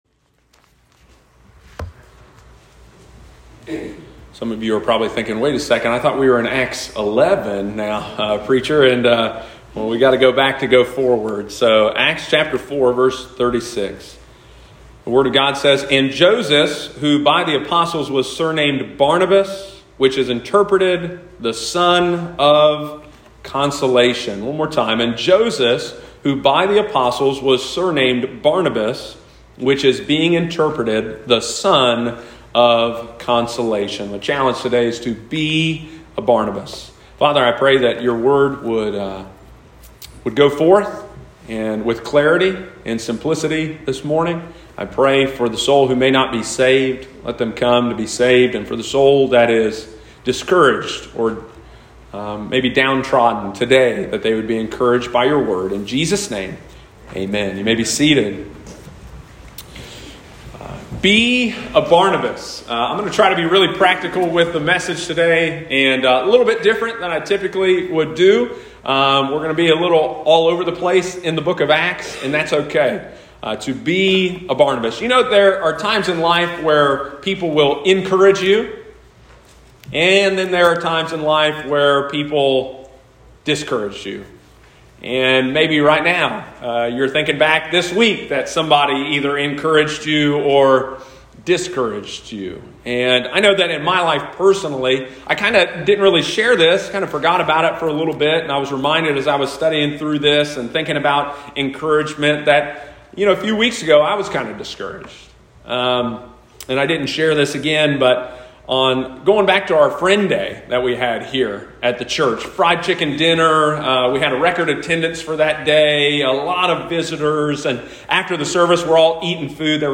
There is a great need in the church today for a ministry of encouragement. Sunday morning, June 19, 2022.